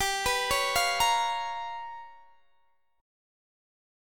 Listen to G7#9b5 strummed